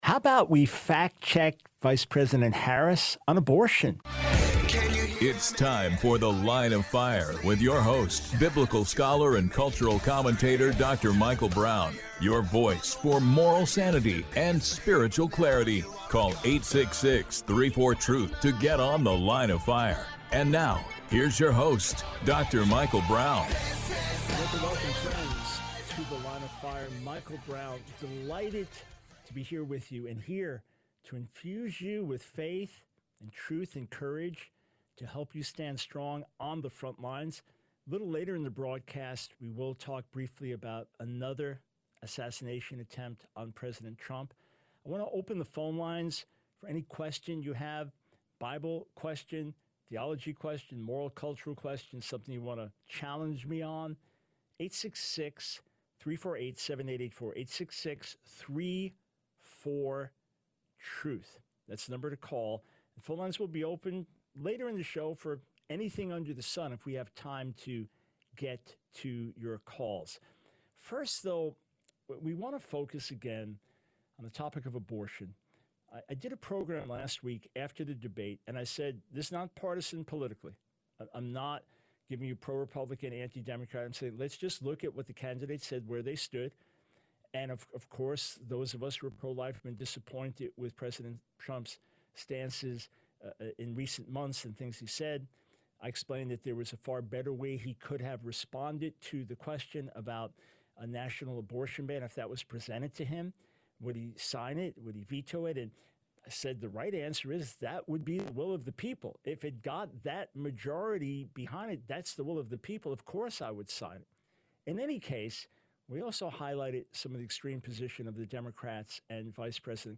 The Line of Fire Radio Broadcast for 09/16/24.